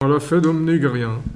Localisation Xanton-Chassenon
Catégorie Locution